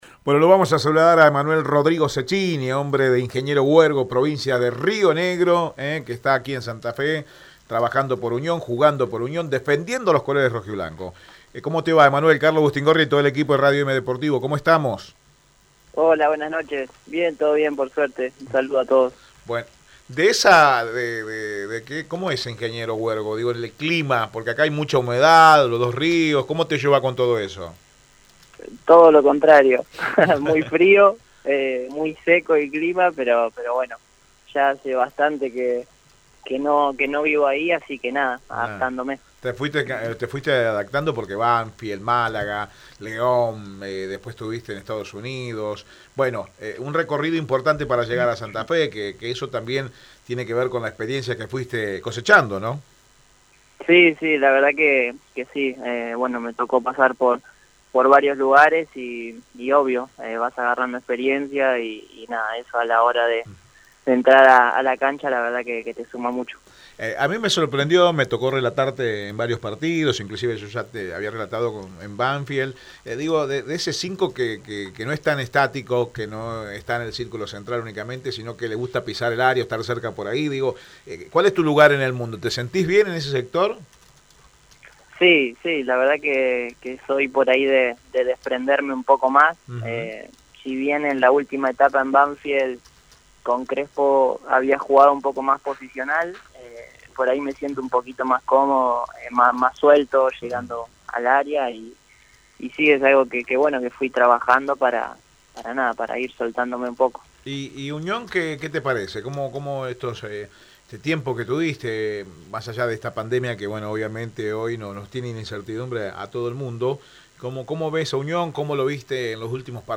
En diálogo exclusivo con Radio EME